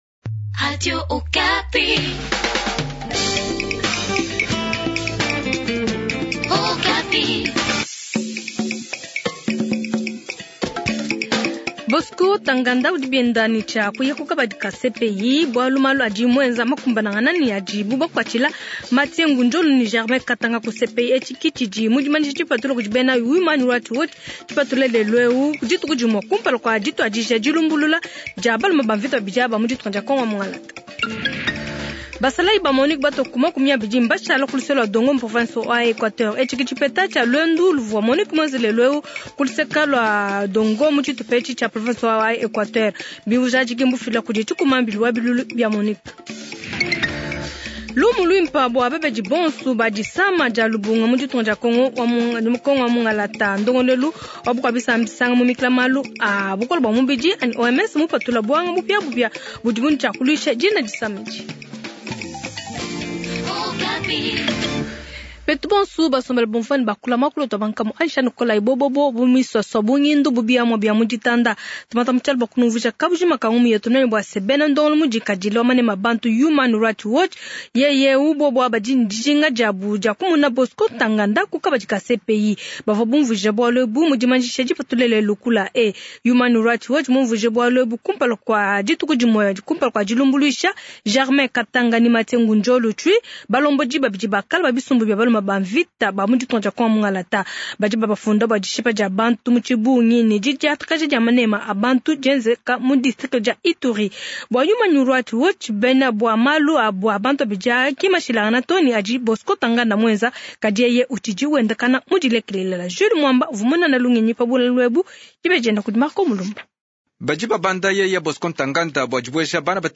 Journal Tshiluba Soir